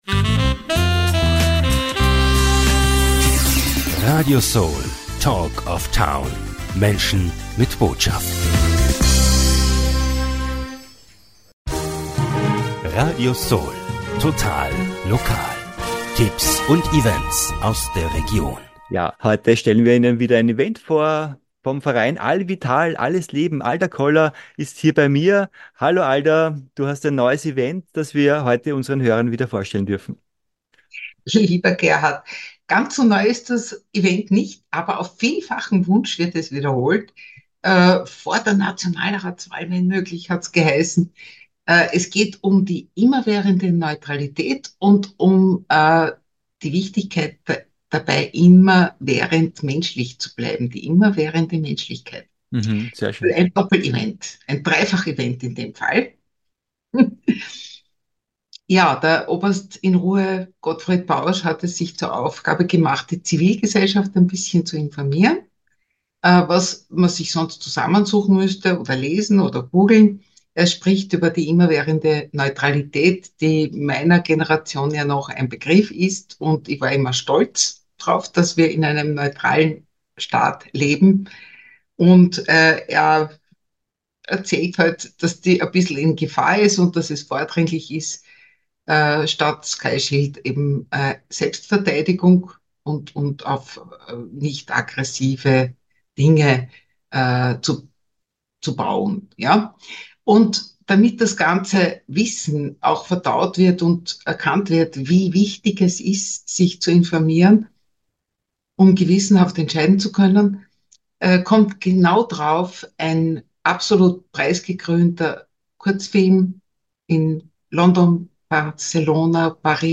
Ab 18.00 Uhr VORTRAG: „DIE BEDEUTUNG IMMERWÄHRENDER NEUTRALITÄT“